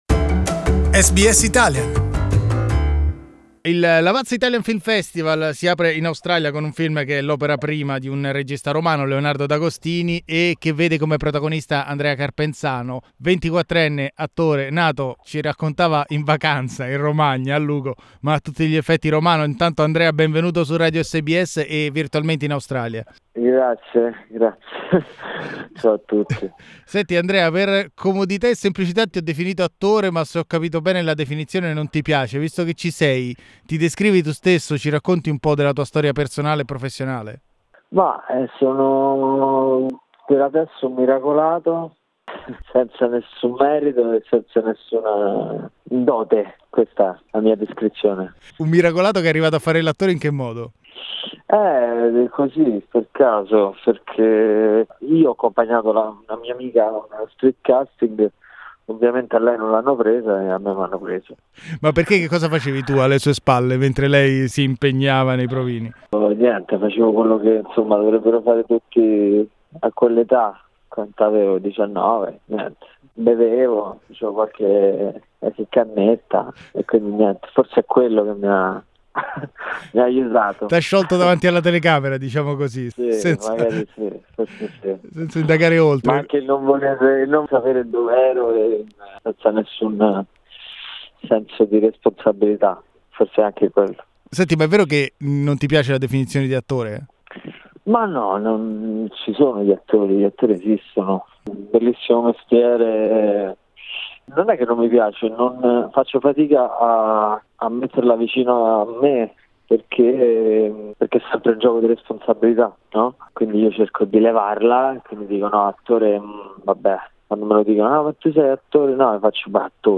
Colloquio semiserio con Andrea Carpenzano, protagonista del film Il Campione.